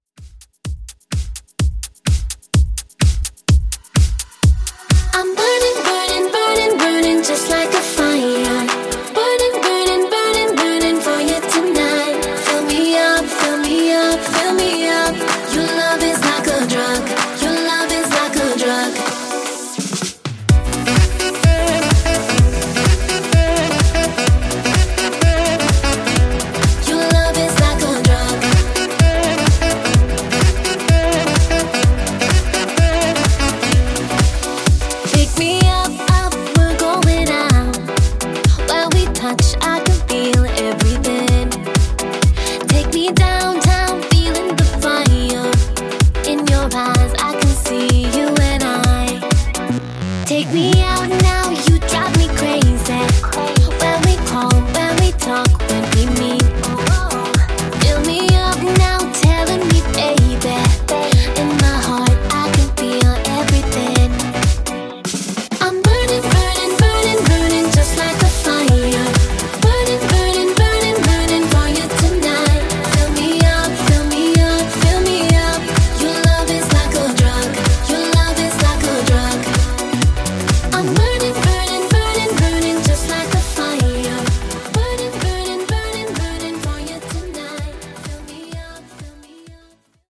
Dance Redrum)Date Added